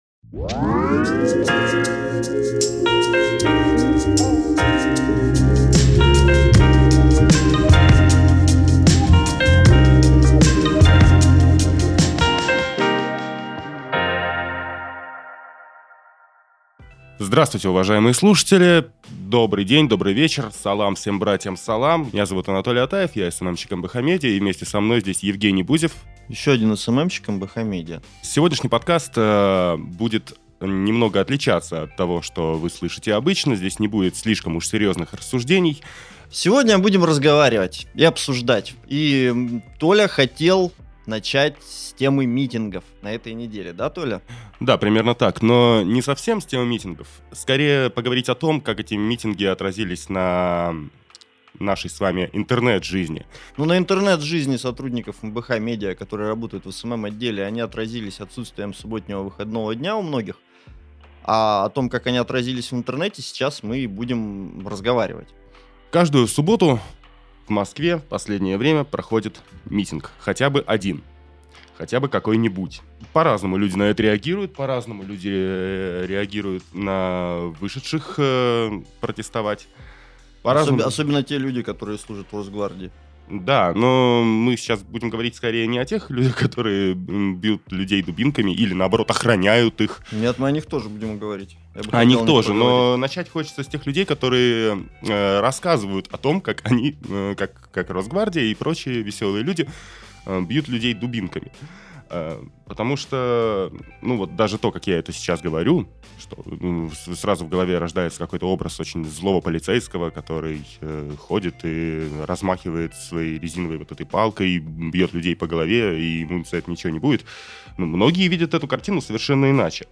Зачем «пригожинские» СМИ хотят быть заблокированными? Это «Субботний «Между нами»» — подкаст, в котором редакторы социальных сетей «МБХ медиа» обсуждают самое важное, смешное, нелепое и абсурдное из произошедшего в российской части интернета за последние две недели.